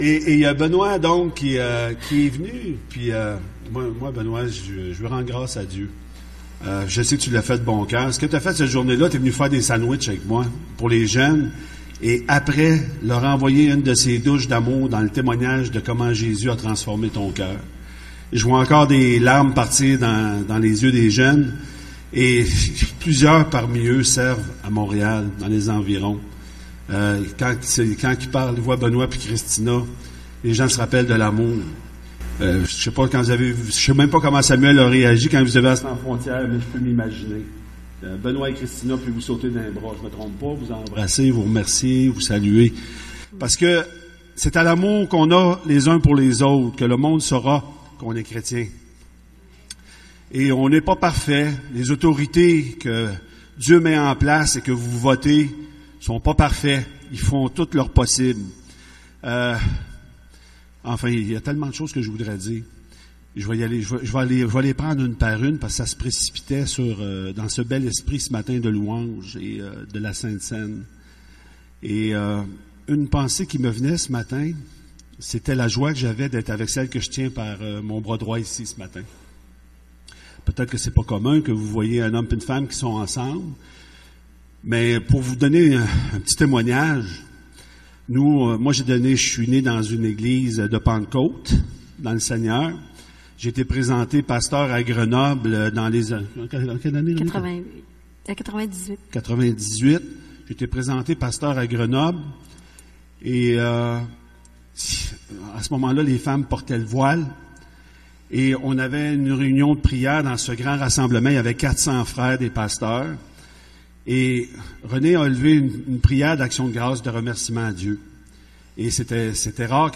Conférence de couple : Marcher dans l'humilité Orgueil et égoisme